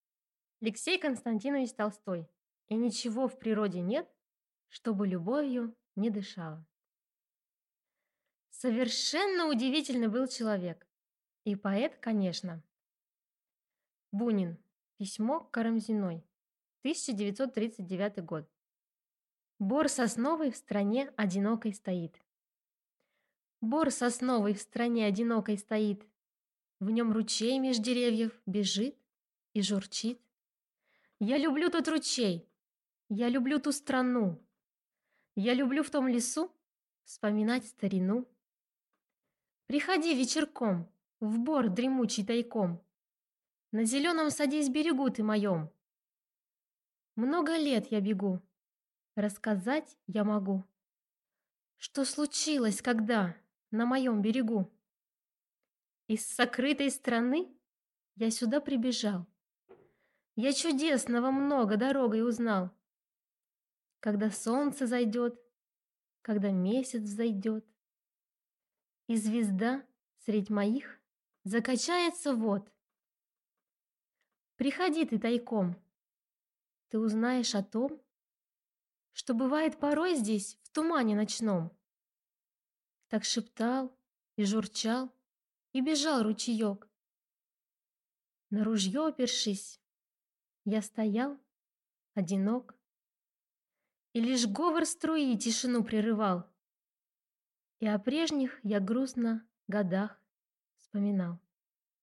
Aудиокнига И ничего в природе нет, что бы любовью не дышало (сборник)